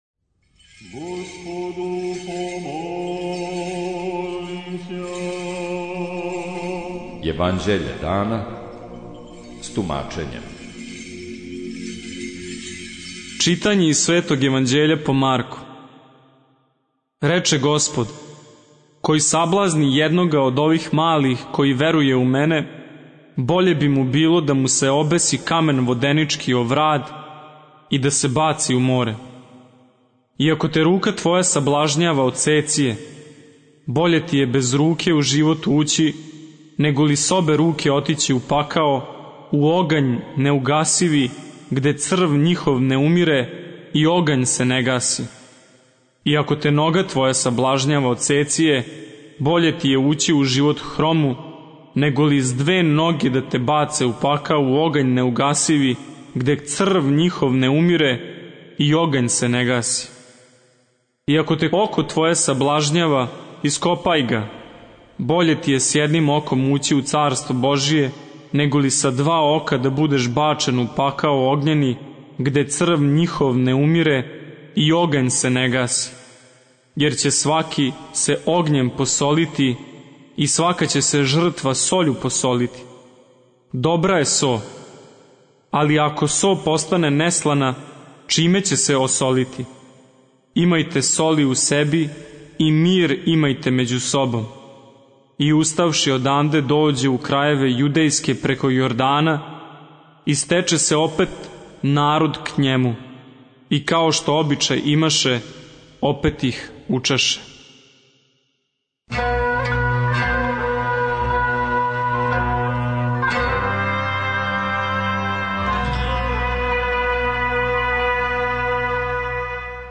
Читање Светог Јеванђеља по Матеју за дан 09.06.2023. Зачало 14.